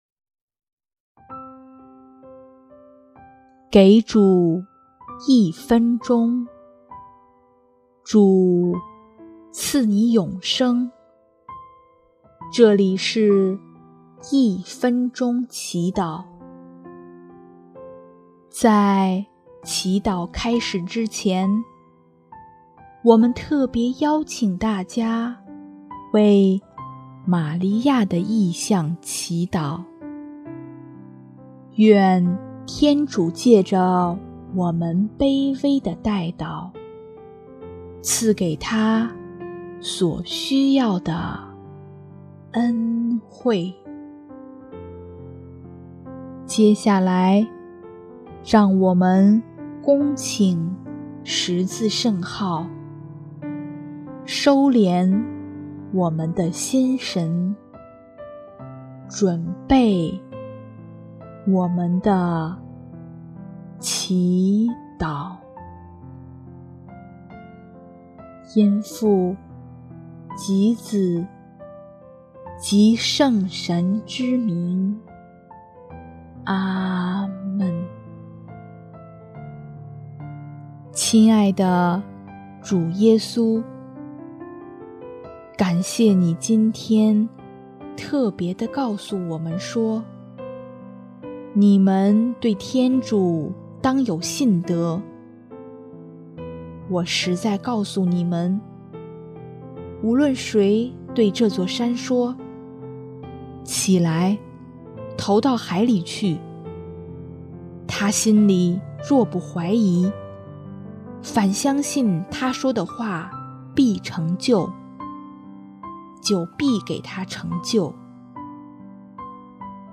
音乐：第三届华语圣歌大赛参赛歌曲《相信》 （玛利亚：医治女儿的身心灵）